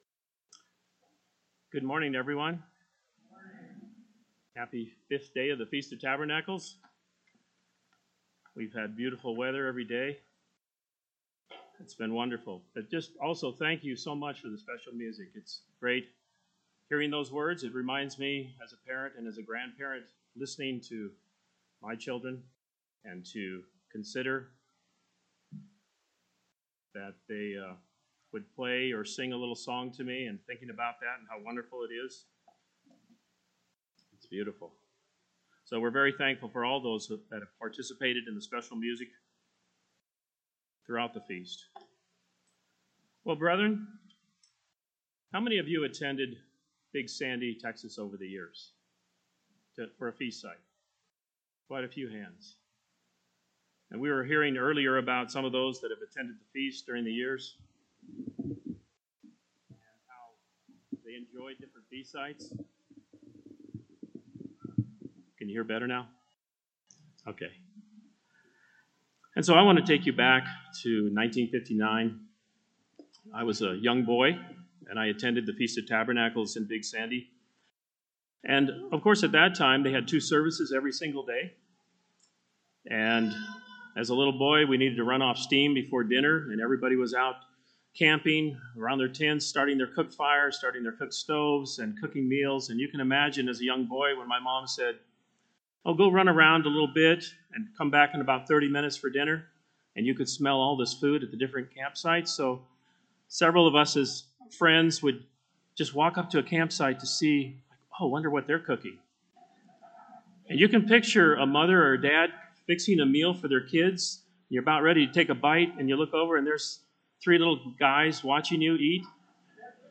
A split sermon given at the Feast of Tabernacles in Glacier Country, Montana, 2020.
This sermon was given at the Glacier Country, Montana 2020 Feast site.